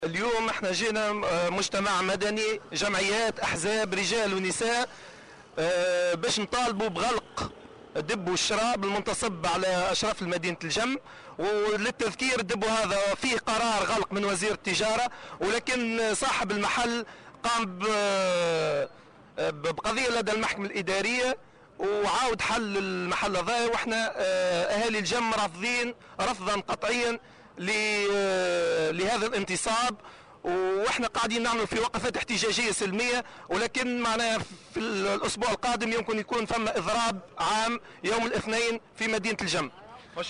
وقال أحد المحتجين في تصريح لمراسل "الجوهرة أف أم" إنهم سيضطرون لتنفيذ إضراب عام بالجهة في صورة عدم غلق نقطة بيع الخمور.